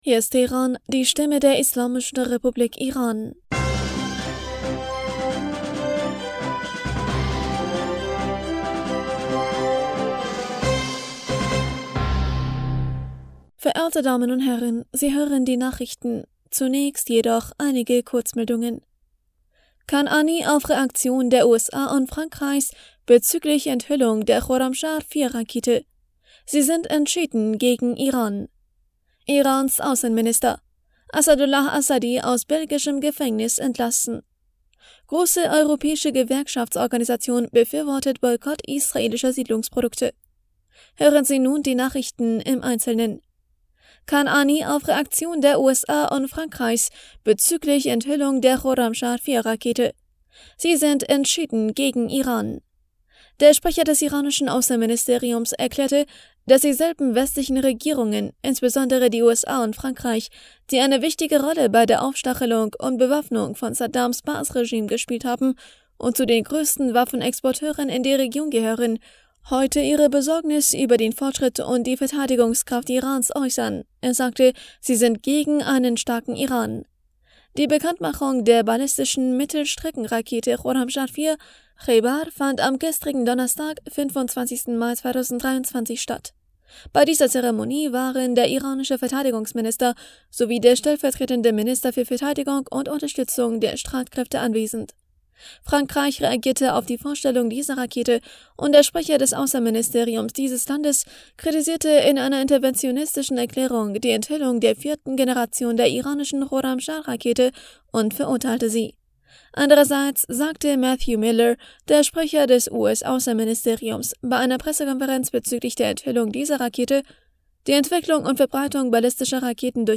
Nachrichten vom 26. Mai 2023